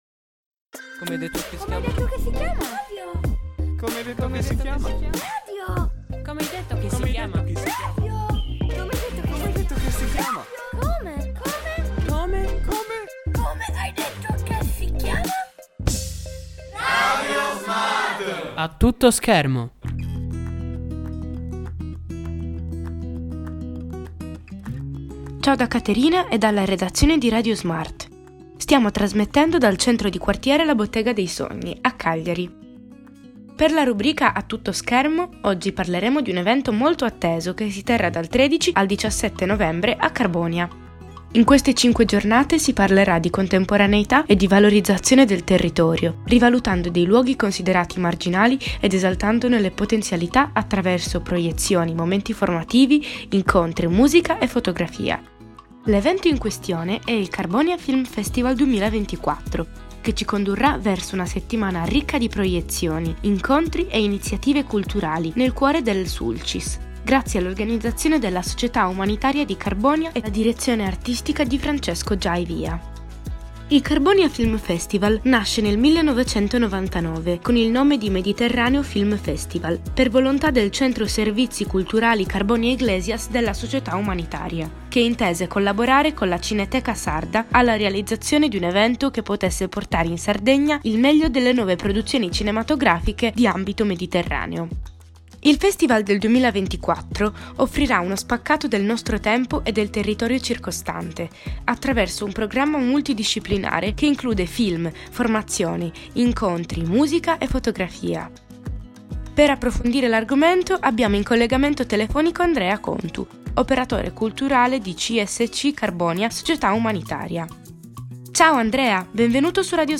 Il festival del 2024 offrirà uno spaccato del nostro tempo e del territorio circostante, attraverso un programma multidisciplinare che include film, formazione, incontri, musica e fotografia. Per approfondire l’argomento abbiamo avuto in collegamento telefonico